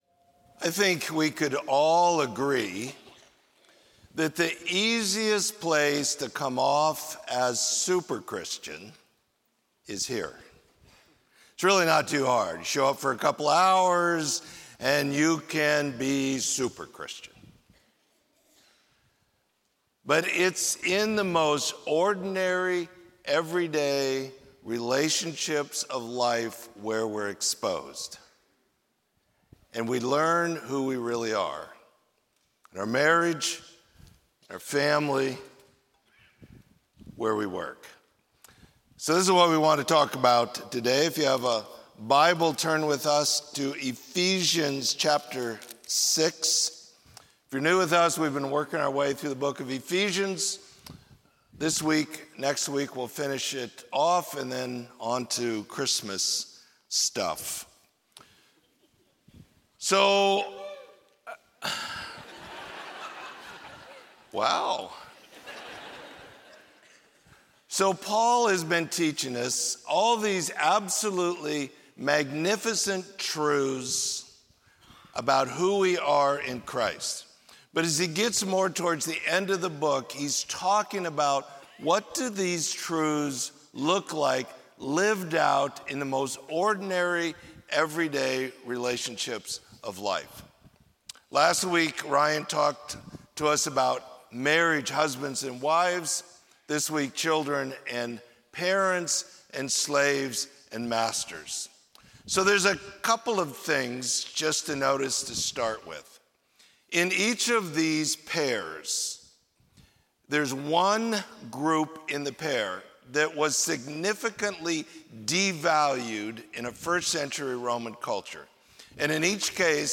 Sermon: Surrendered to the Spirit: Family and Work